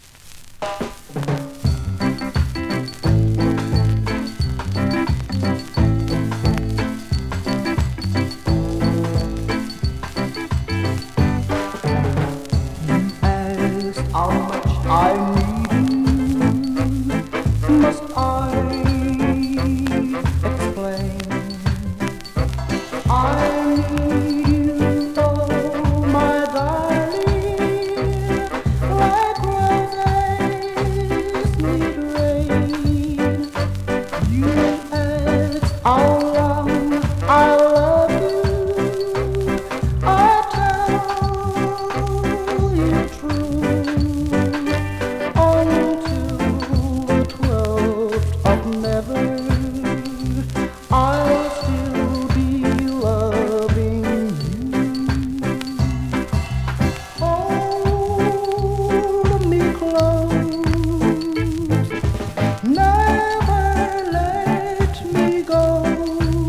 (両面ともクモリ有、うすくジリノイズ有)
スリキズ、ノイズ比較的少なめで